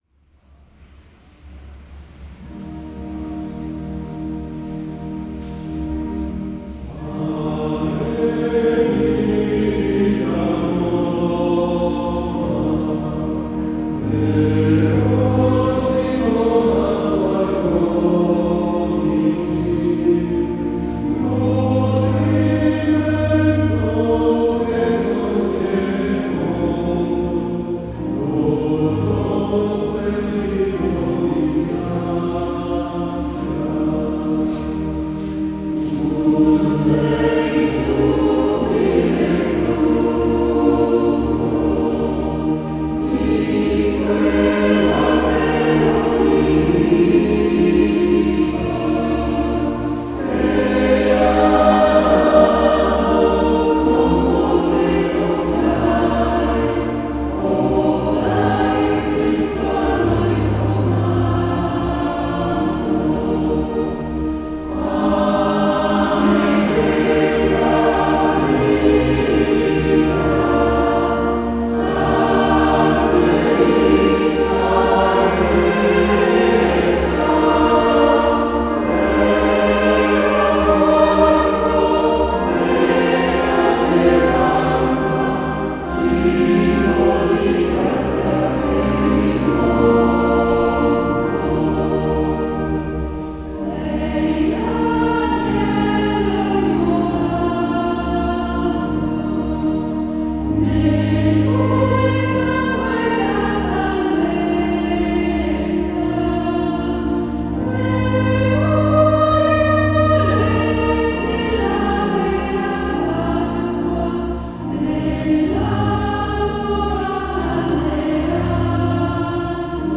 SCHOLA CANTORUM Sedico (Belluno)
Sedico 25_03_2025